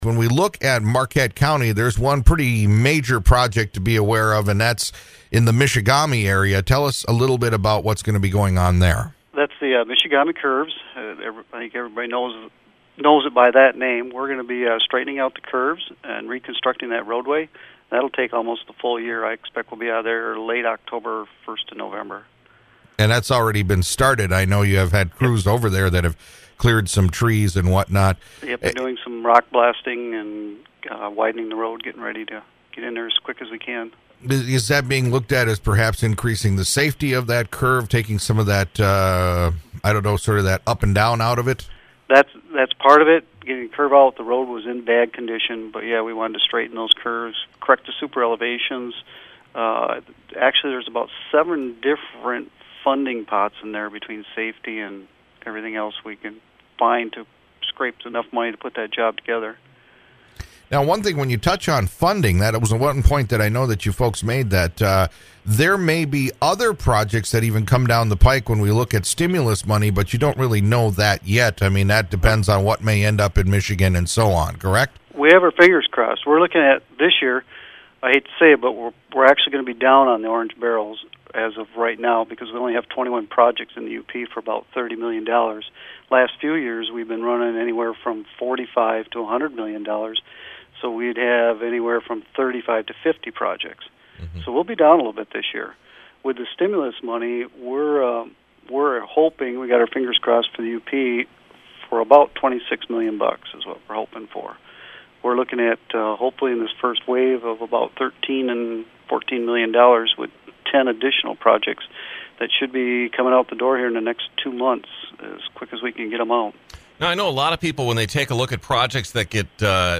Old Interviews Archive